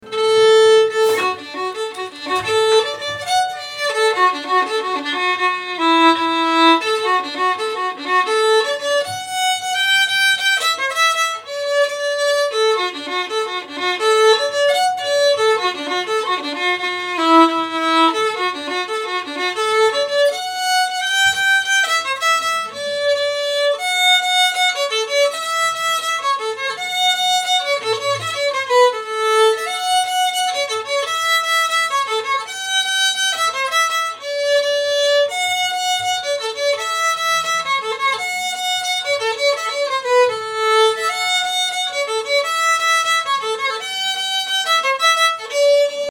Engelska